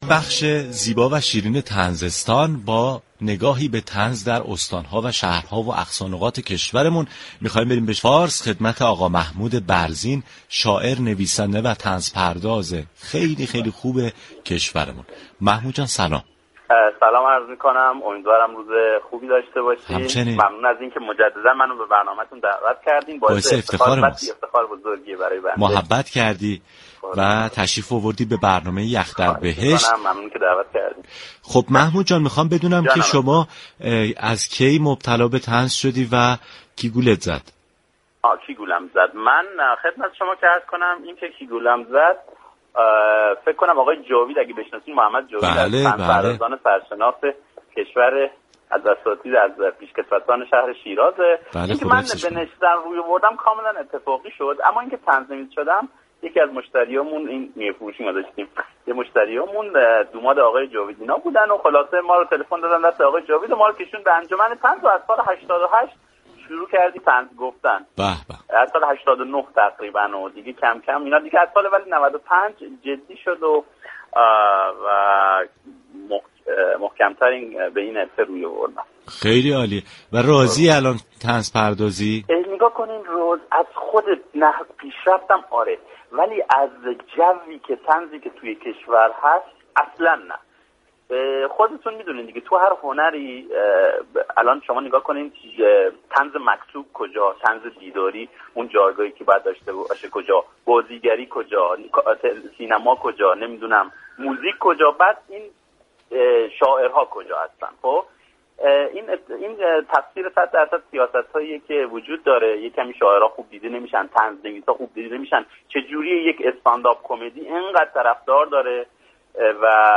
گفتگوی تلفنی